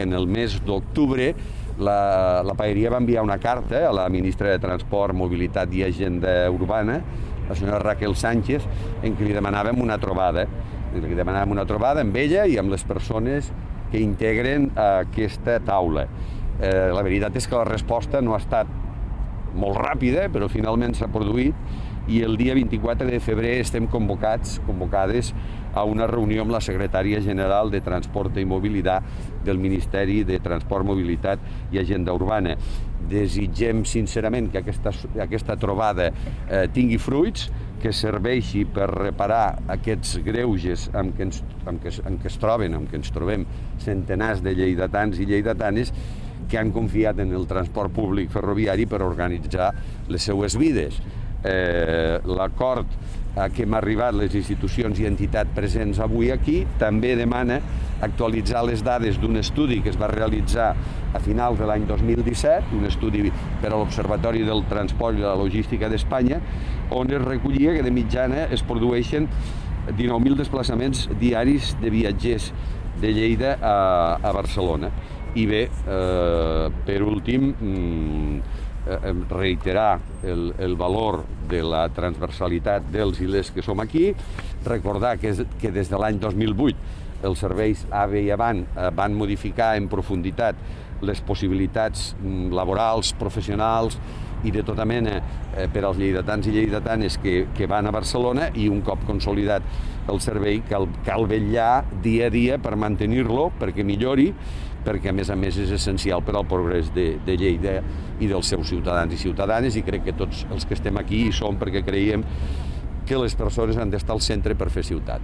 tall-de-veu-del-paer-en-cap-miquel-pueyo